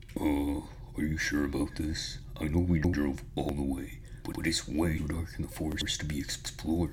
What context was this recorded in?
Audio is distorted and messed up and I don't know how to fix I recorded about an hours worth of gameplay and I record my voice in different software, but this time it recorded all distorted and everything.